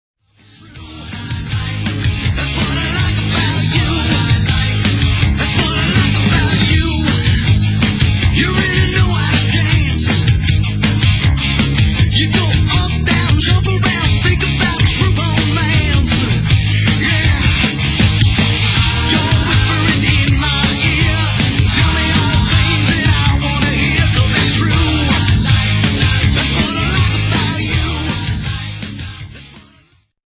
Vocals, Guitar, Harmonica
Lead Guitar, Vocals
Drums, Vocals
Bass, Vocals